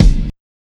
KICKPUNCH.wav